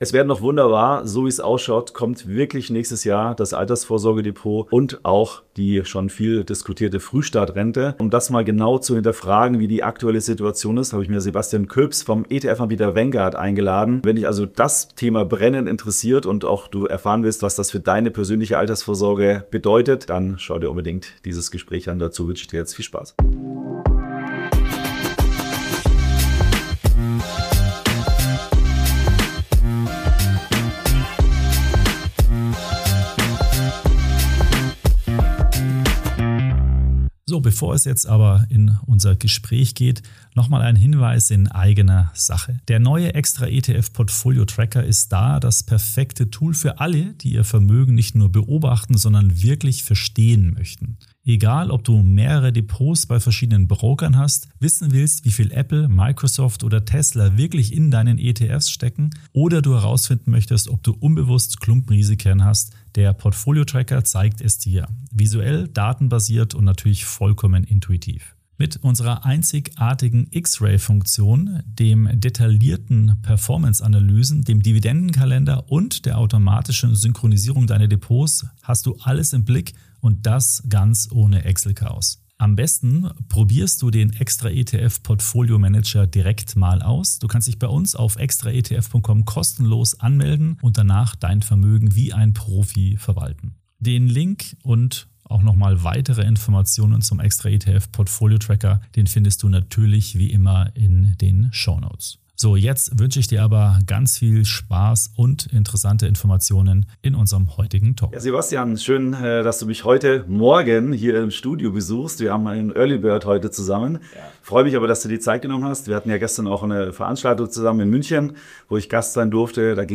Ein Pflicht-Interview für alle, die langfristig investieren und ihre Altersvorsorge modern aufstellen möchten!